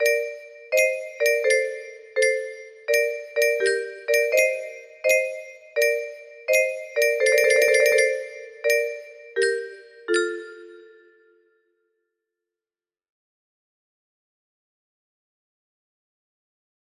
rest music box melody
Grand Illusions 30 (F scale)